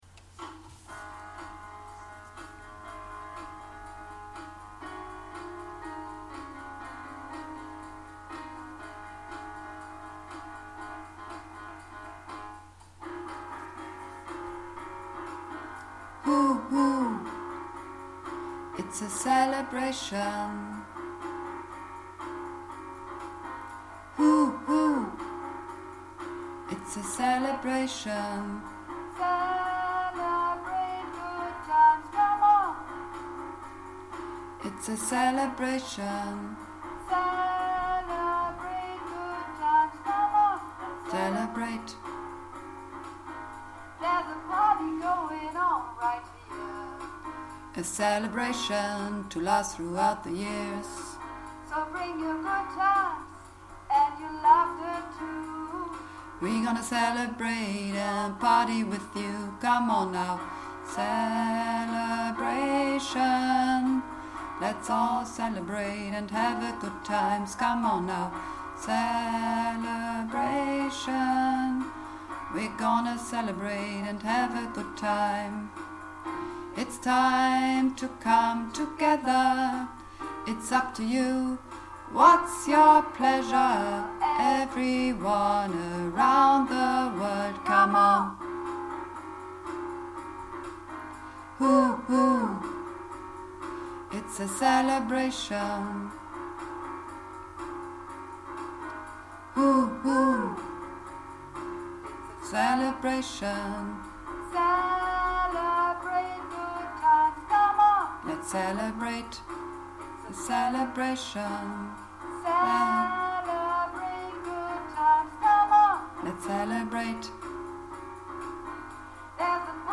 (Übungsaufnahmen)
Celebration_-_Bass.mp3